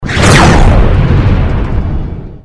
ships / combat / youhit5.wav
youhit5.wav